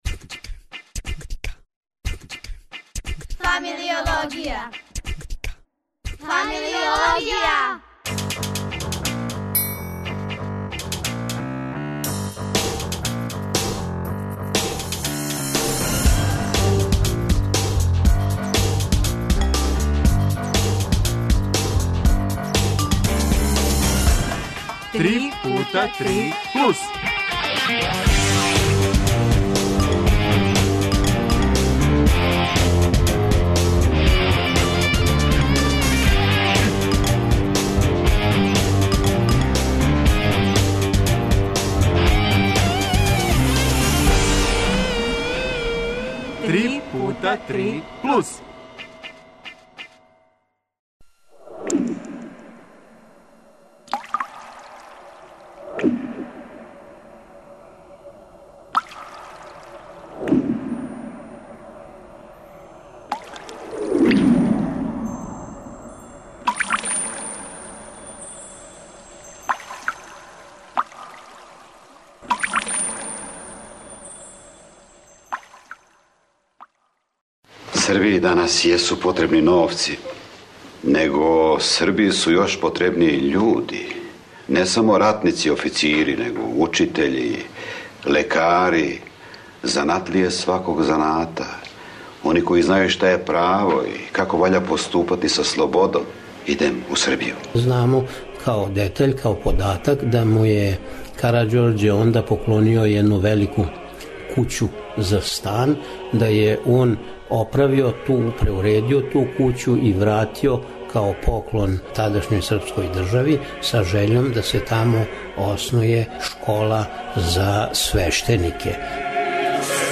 Прeд oдлaзaк нaших прeдстaвникa у Кeмбриџ нa мeђунaрoдну лингвистичку кoнфeрeнциjу, дaнaшњу eмисиjу 3путa3 + рeaлизуjeмo из Tршићa.